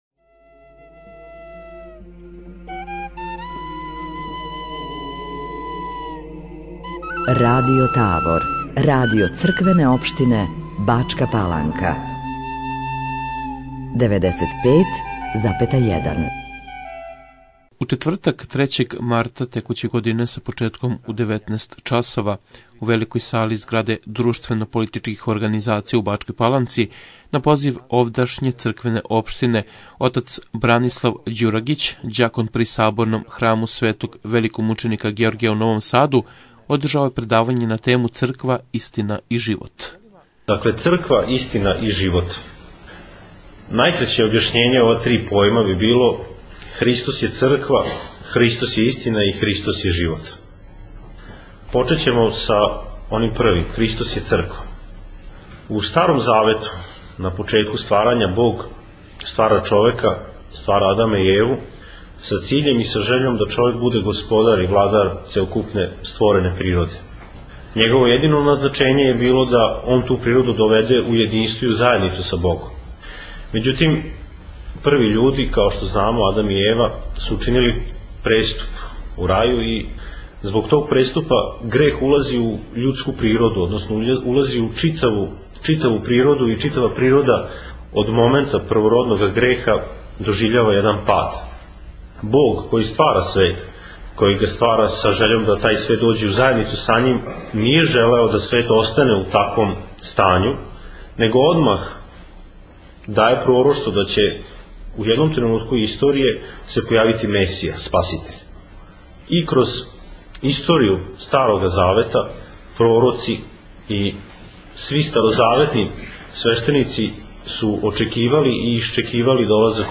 Бачка Паланка